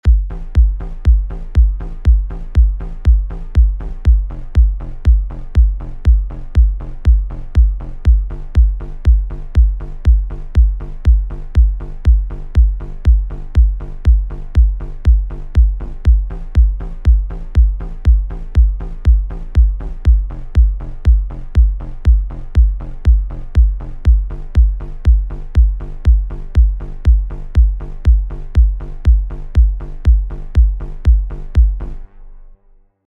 You really want to layer sounds.
Here is my take with really exaggerated sounds to hear them all.